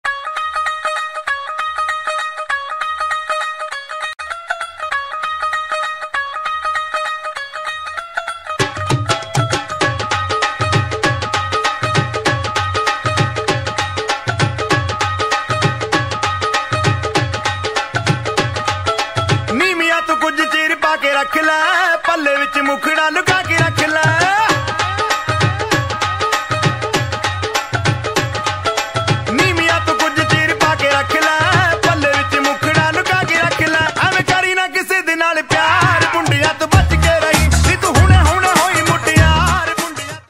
• Качество: 320 kbps, Stereo
Индийские